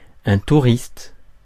Ääntäminen
Ääntäminen : IPA: /tu.ʁist/ Haettu sana löytyi näillä lähdekielillä: ranska Käännös Konteksti Ääninäyte Substantiivit 1. tourist 2. dude slangi US Suku: m .